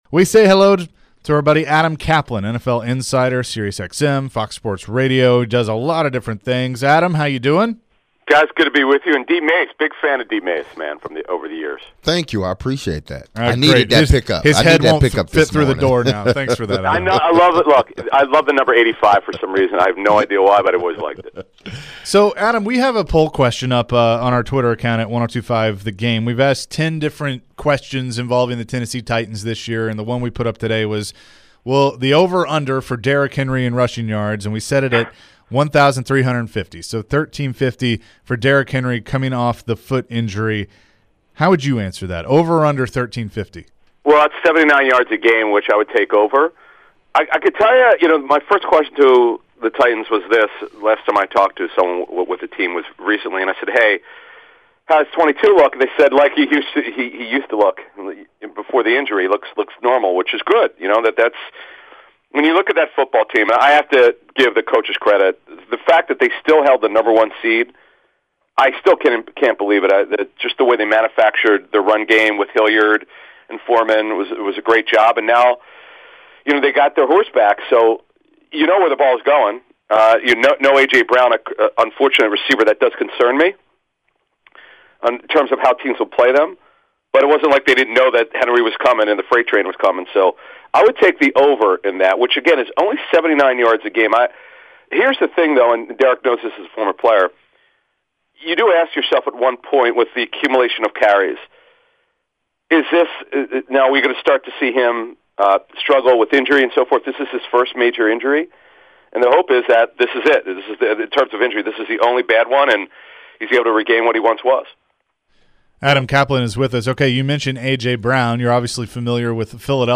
full interview